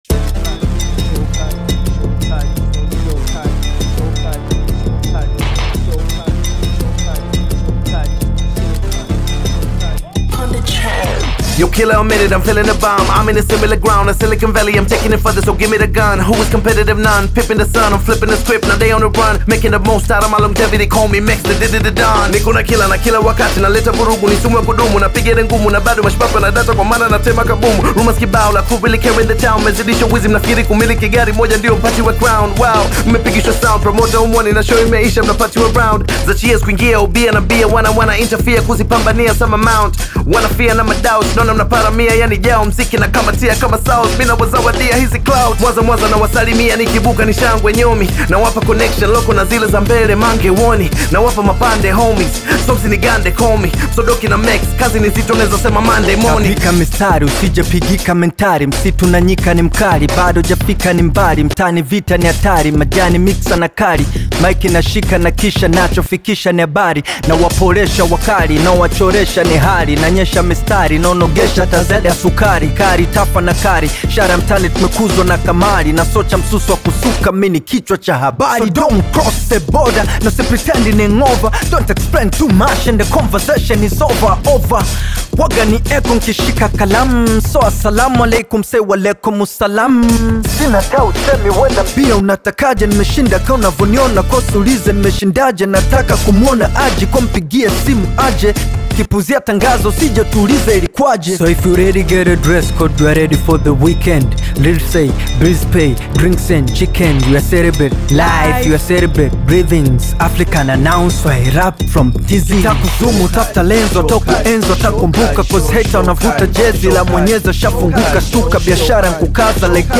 AudioBongo Hip-HopTanzanian Music